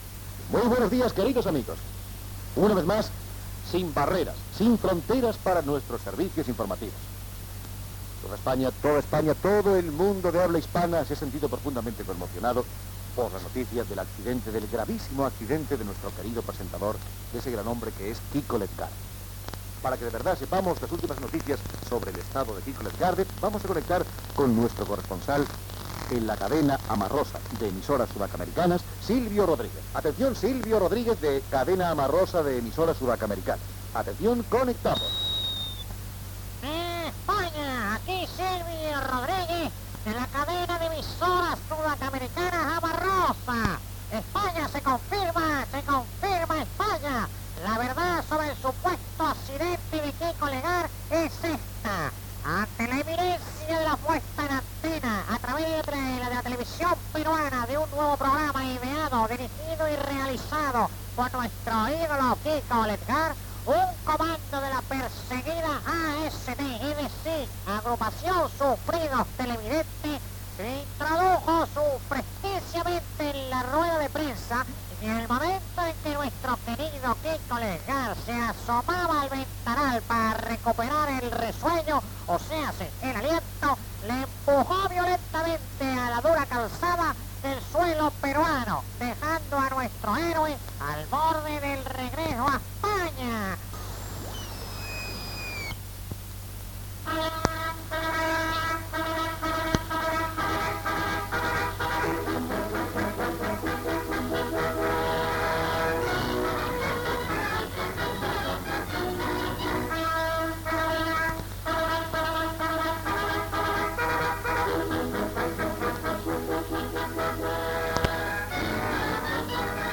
trucada a la periodista Paloma Gómez Borrego que està a Roma Gènere radiofònic Entreteniment